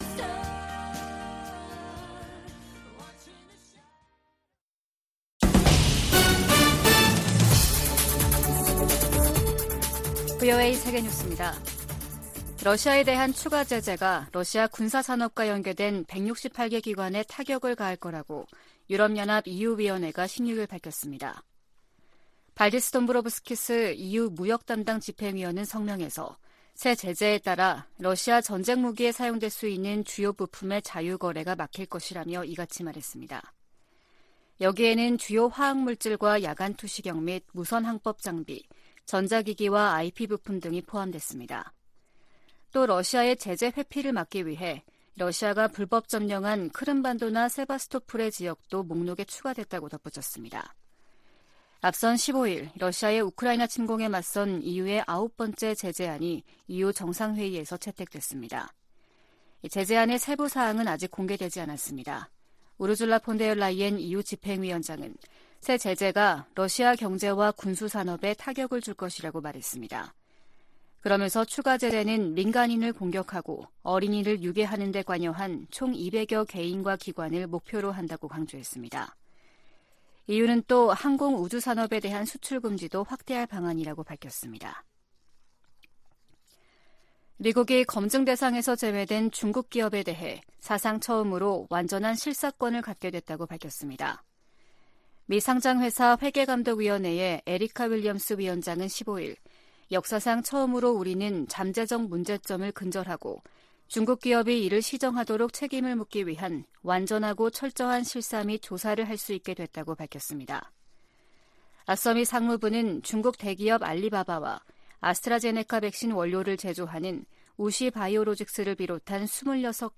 VOA 한국어 아침 뉴스 프로그램 '워싱턴 뉴스 광장' 2022년 12월 16일 방송입니다. 북한이 대륙간탄도미사일로 보이는 고출력 고체엔진 시험에 성공했다고 주장했습니다.